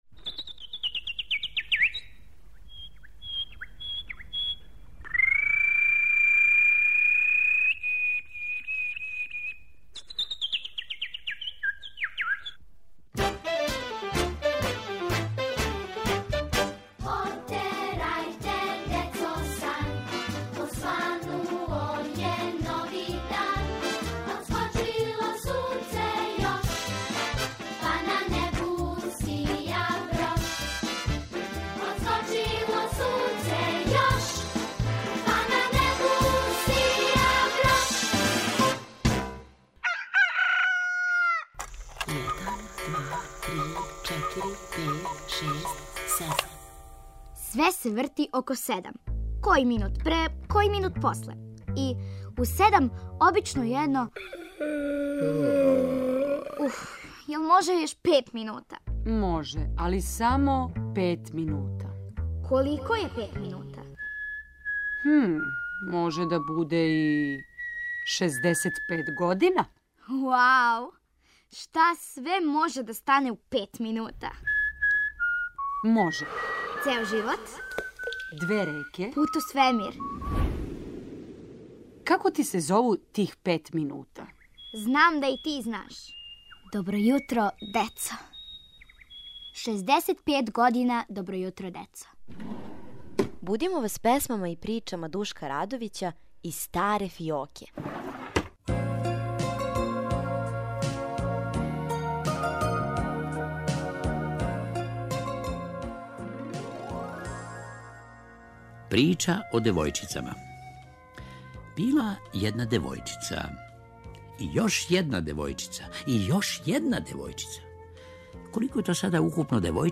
Поводом 65. рођендана емисије "Добро јутро децо", будимо вас песмама и причама Душка Радовића из старе фиоке.